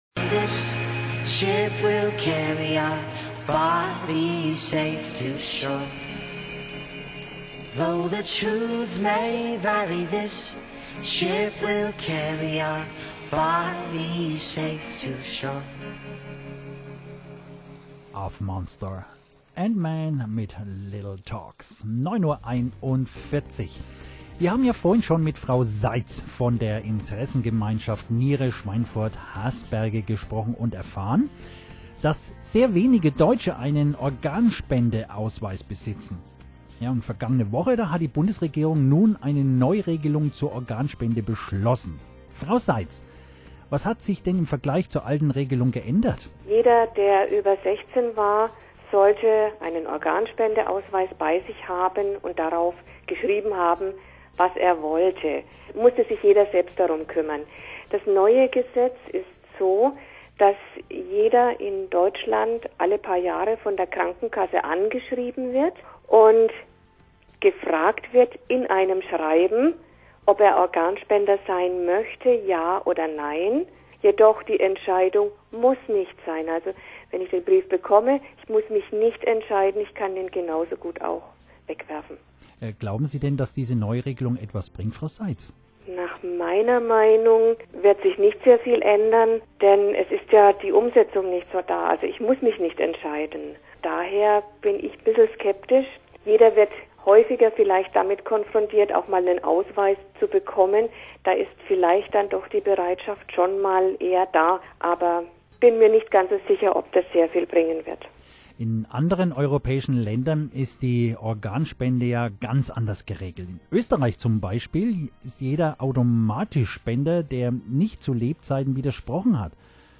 Interview am Tag der Organspende zum neuen Transplantationsgesetz - Teil 2